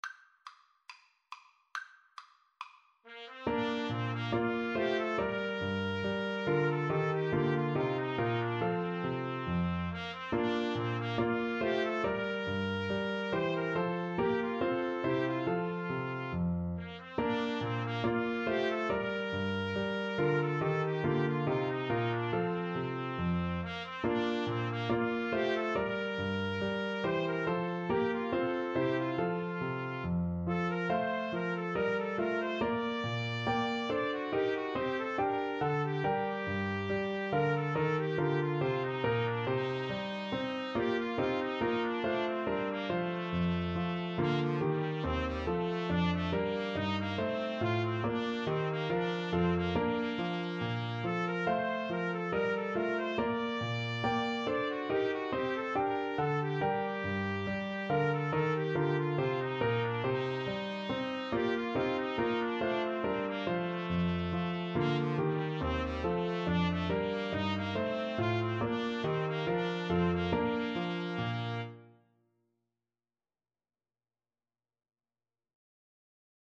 Play (or use space bar on your keyboard) Pause Music Playalong - Player 1 Accompaniment reset tempo print settings full screen
Bb major (Sounding Pitch) (View more Bb major Music for Trumpet-French Horn Duet )